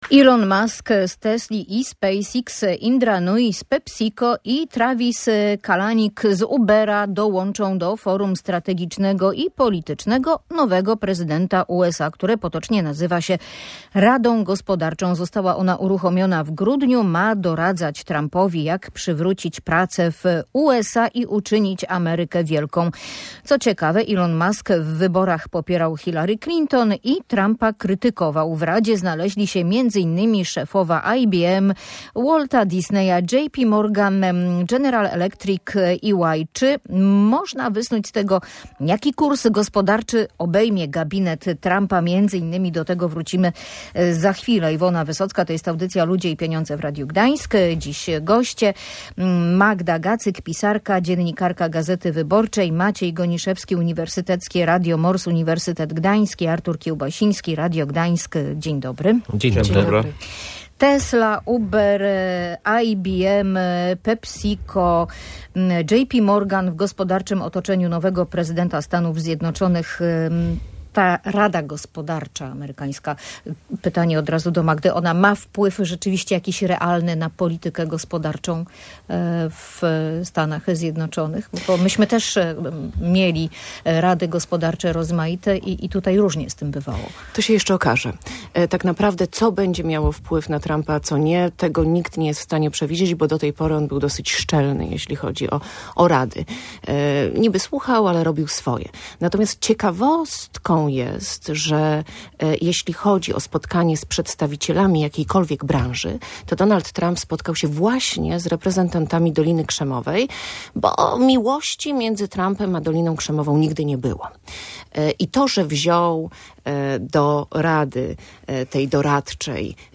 O Forum Strategicznym i Politycznym Prezydenta USA rozmawiali eksperci audycji Ludzie i Pieniądze.